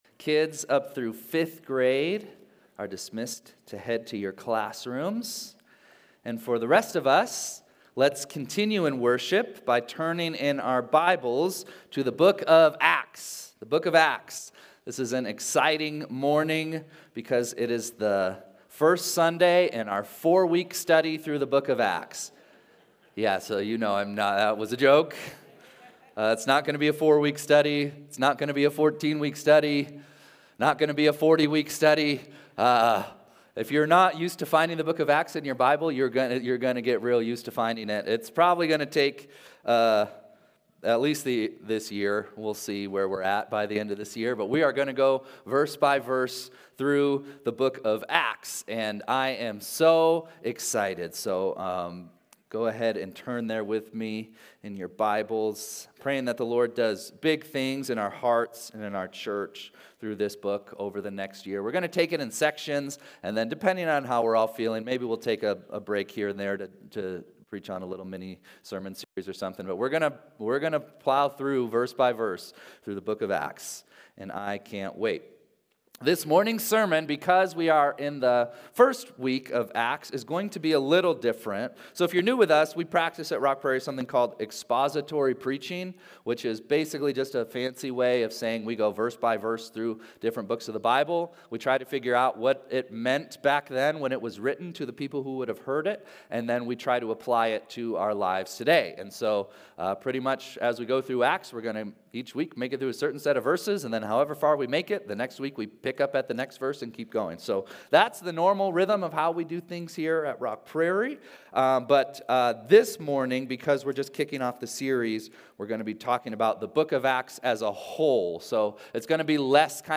1-12-25-Sunday-Service.mp3